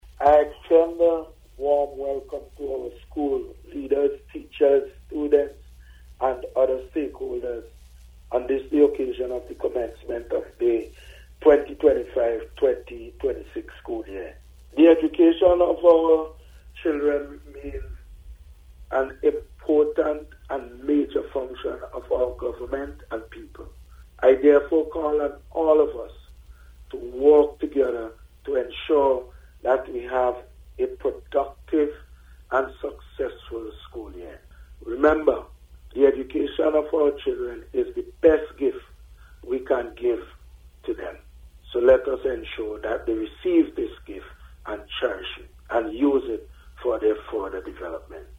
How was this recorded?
He made this statement during his back to School Message marking the start of the new academic year.